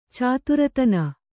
♪ cāturatana